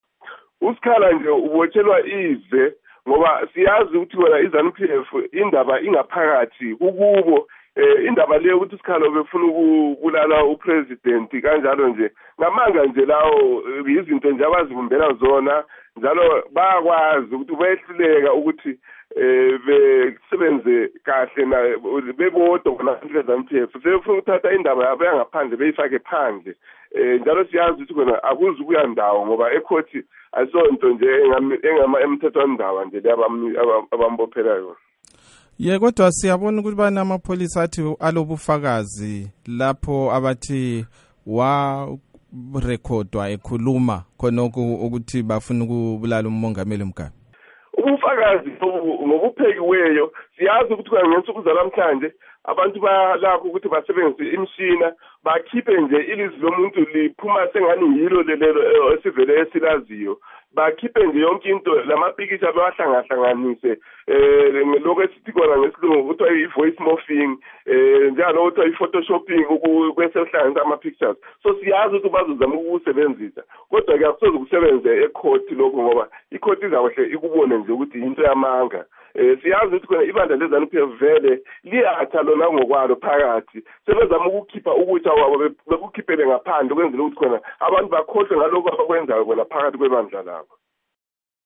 Sixox lengcwethi ecubungula ezombusazwe, uMnu.
Ingxoxo loMnu.